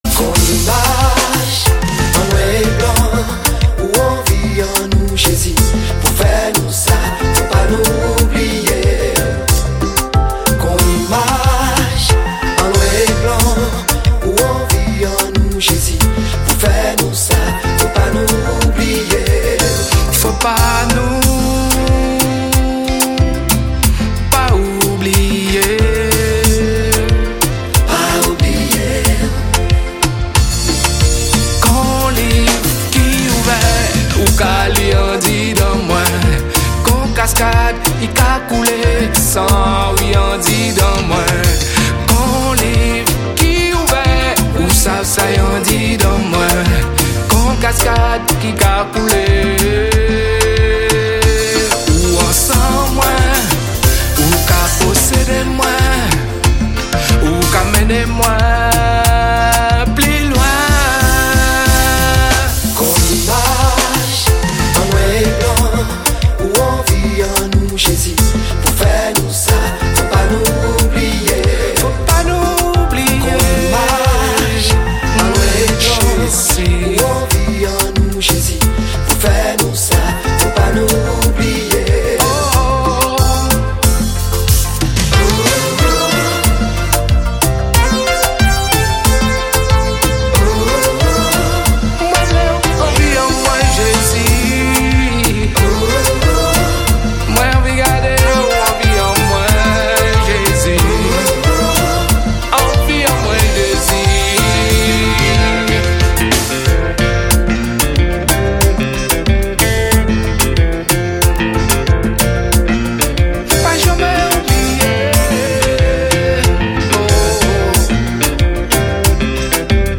Zouk, salsa, kompa, séga, maloya...Anecdotes et non-stop musique des Caraïbes et de l'Océan Indien.